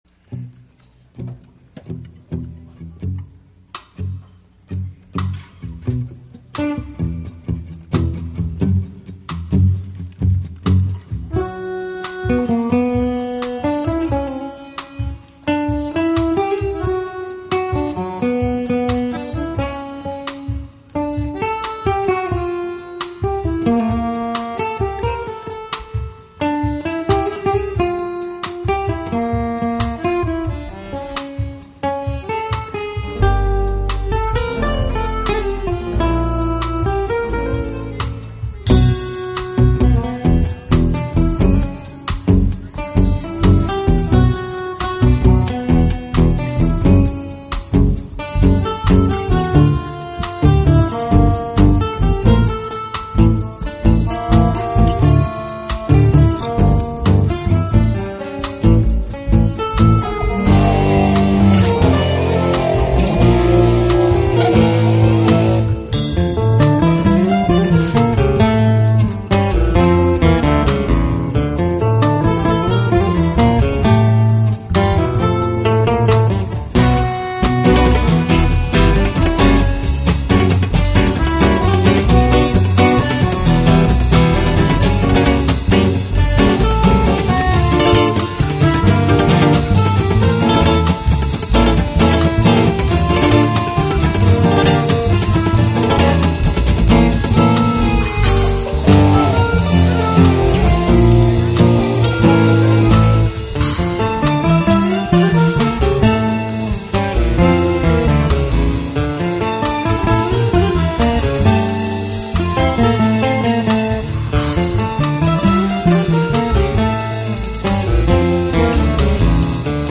【爵士吉他】